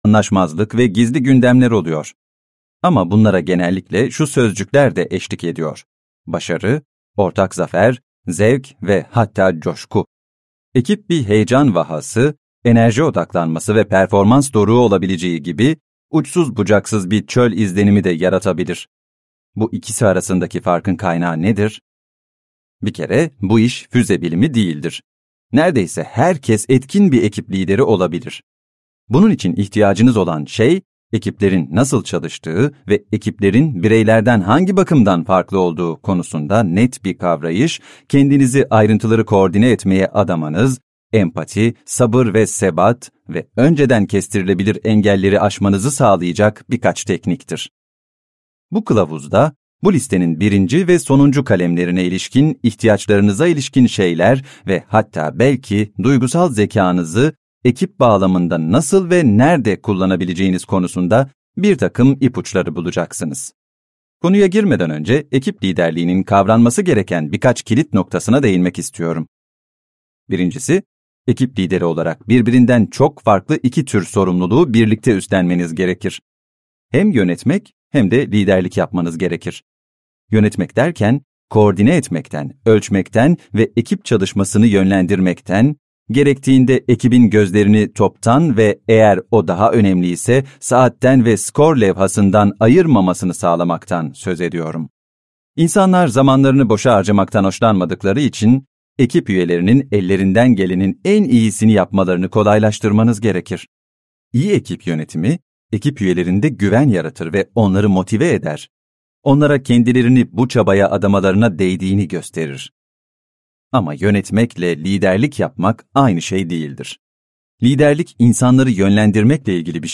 Seslendiren